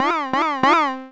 retro_jump_dizzy_spin_01.wav